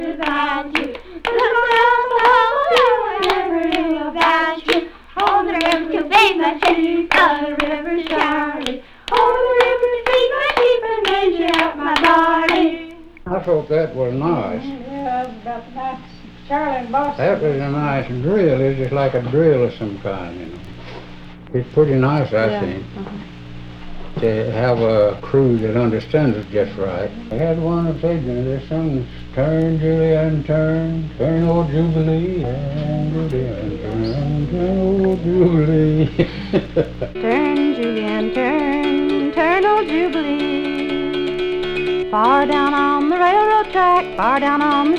Folk, World, Apparachian Music　USA　12inchレコード　33rpm　Stereo